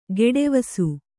♪ geḍevasu